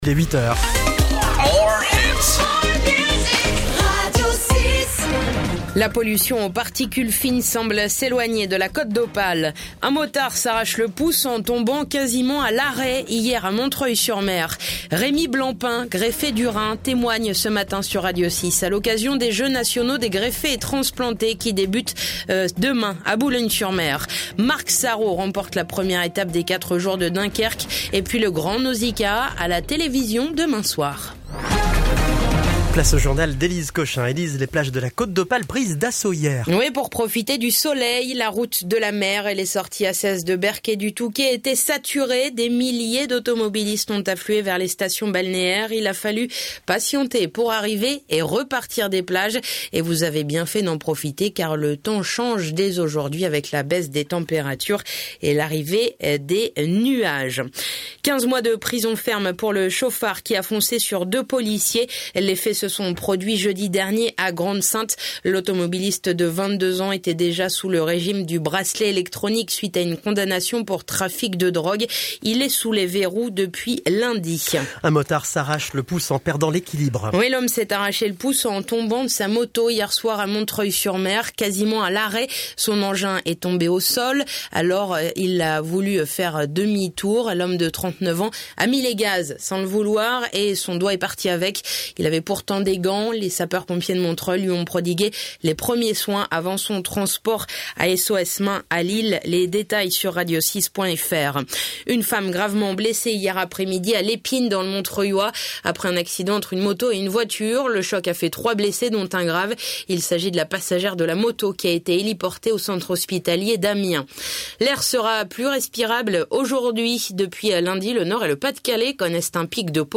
L'essentiel de l'actu de la Côte d'Opale et les titres de l'actu nationale et internationale dans un journal de 6 minutes ! (édition de 8h)